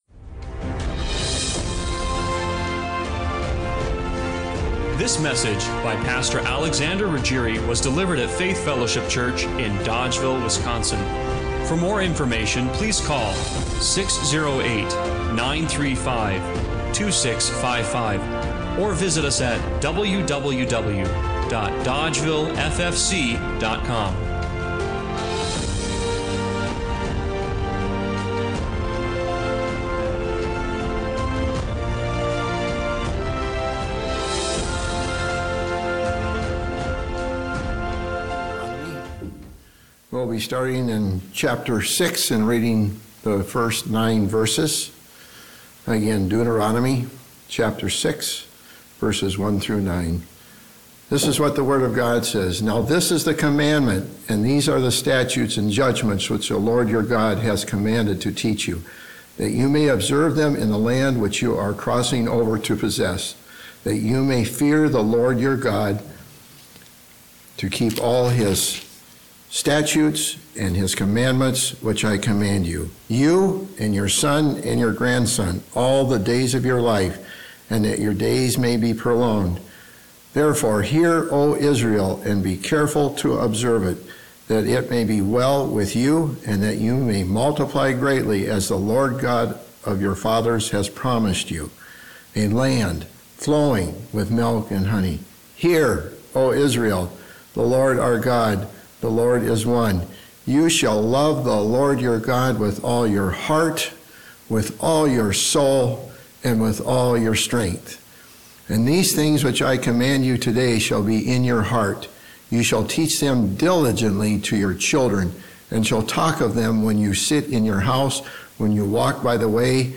Passage: Deuteronomy 6:4 Service Type: Sunday Morning Worship Why do you go to church?